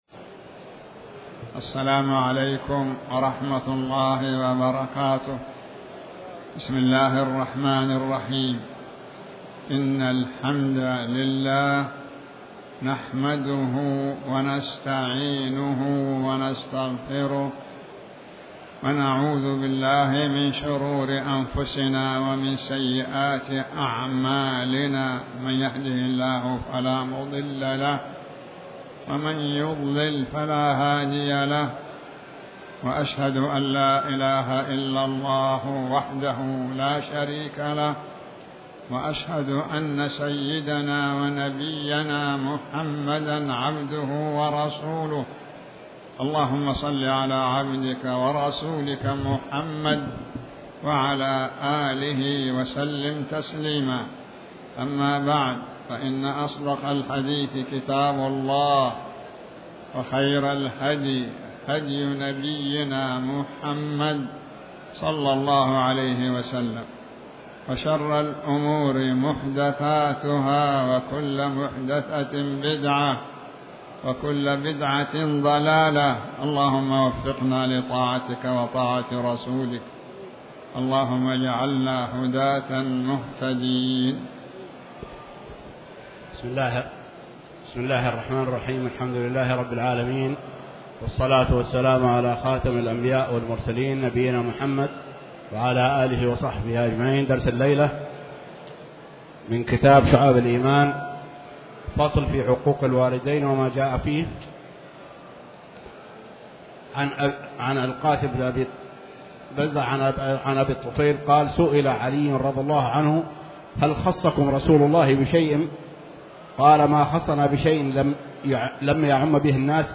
تاريخ النشر ٢٠ محرم ١٤٤٠ هـ المكان: المسجد الحرام الشيخ